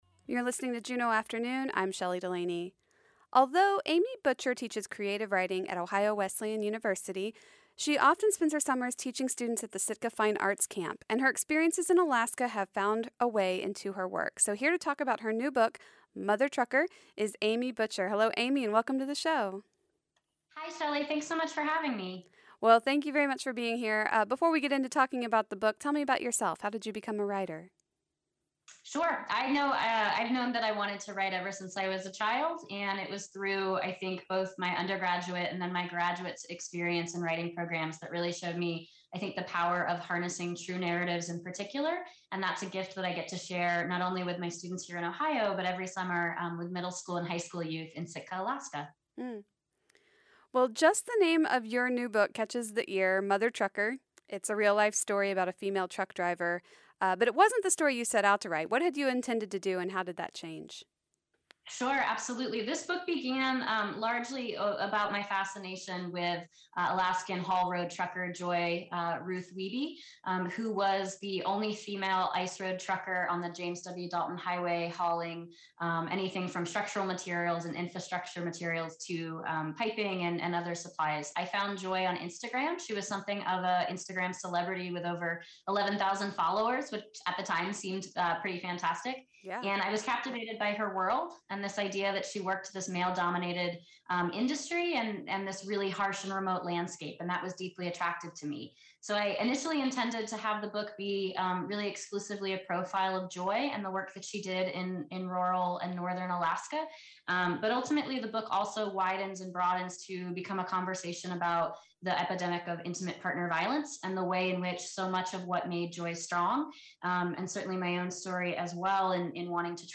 Also in today’s program: A conversation with Farai Chideya, about her show, Our Body Politic First hand lessons in entrepreneurship at the Montessori Borealis school. Montessori Borealis Students make their own teas with plants they gather themselves.